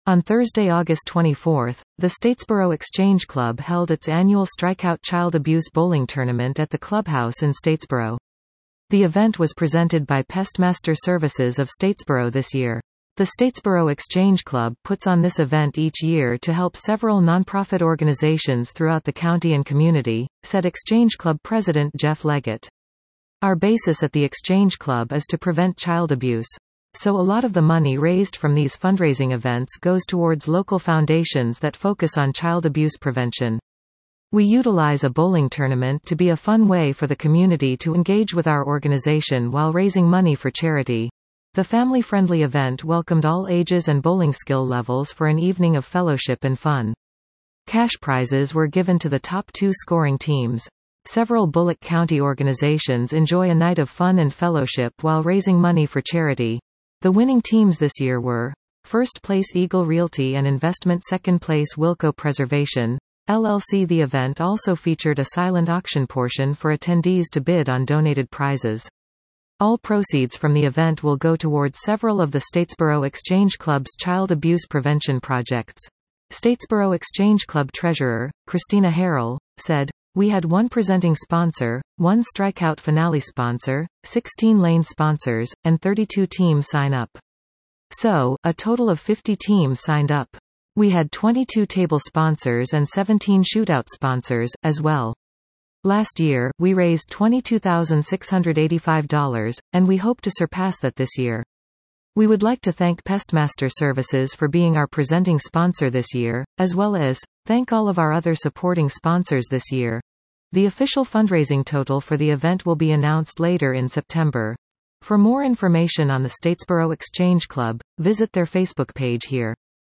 Advertisement Expand Listen to this article 00:02:00 On Thursday, August 24th, the Statesboro Exchange Club held its annual Strikeout Child Abuse Bowling Tournament at The Clubhouse in Statesboro.